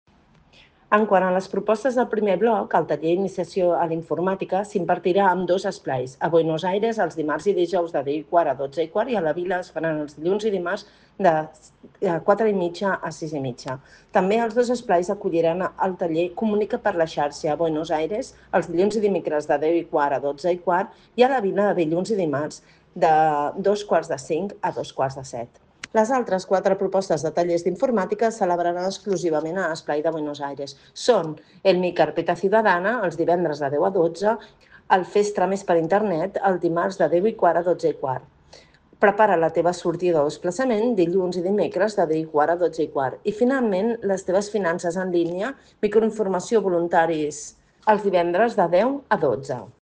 Montserrat Salas, regidora de Gent Gran de l'Ajuntament